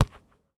Ball Pass Normal.wav